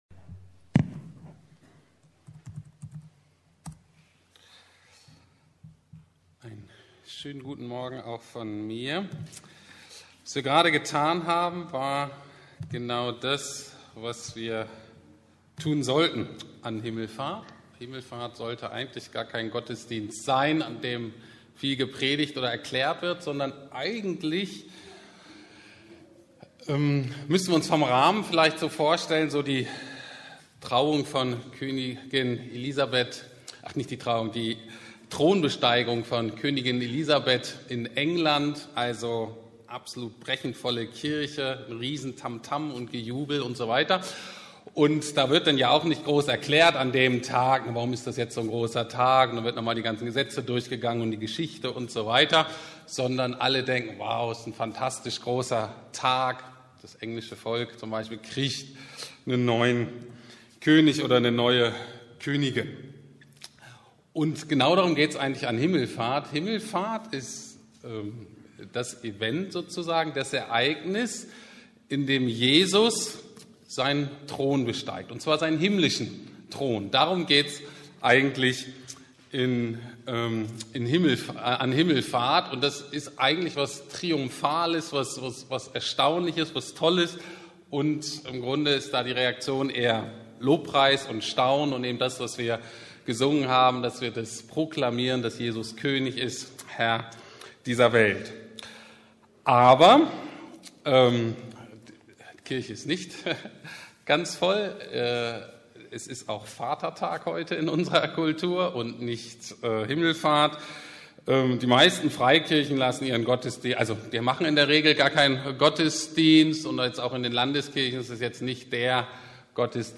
Himmelfahrt: der König und sein Herrschaftsgebiet Teil 1 ~ Predigten der LUKAS GEMEINDE Podcast